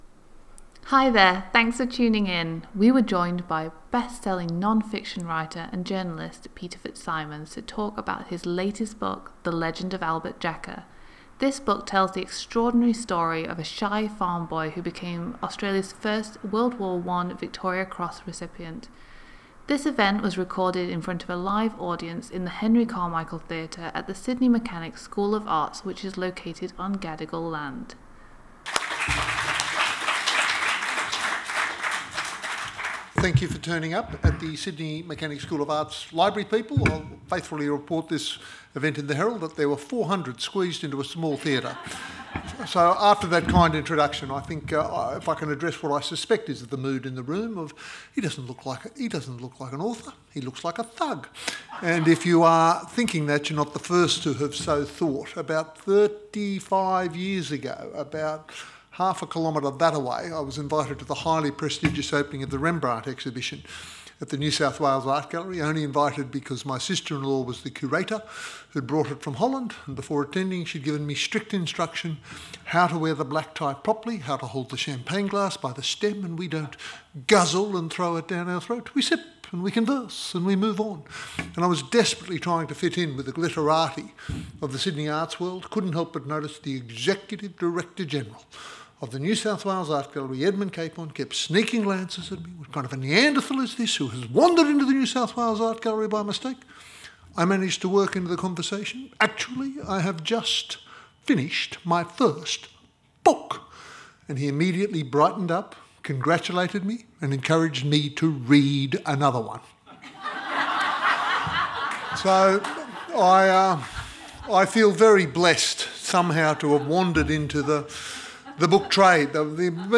Listen Again: Peter FitzSimons Author Talk
We were joined by Australia’s bestselling non-fiction writer and journalist, Peter FitzSimons to talk about his latest book – The Legend of Albert Jacka.
Peter-FitzSimons-SMSA-Author-Talk-Recording.mp3